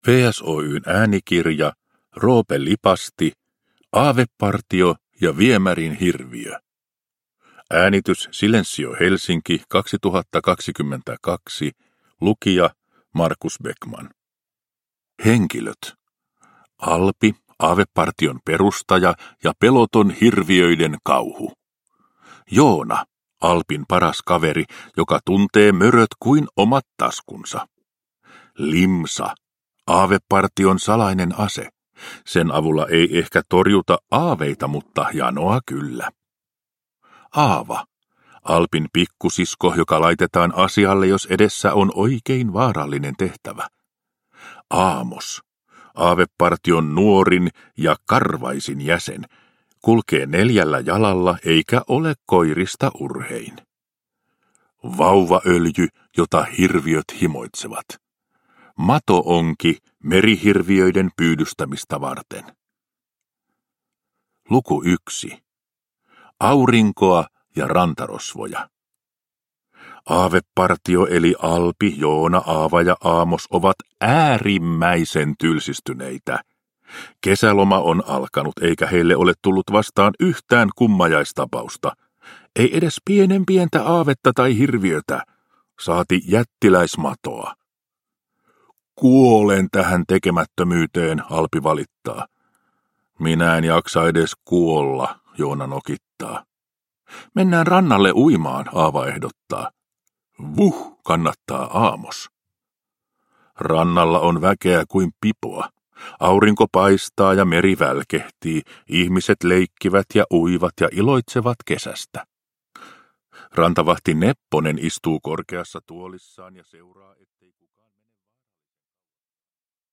Aavepartio ja viemärin hirviö – Ljudbok
• Ljudbok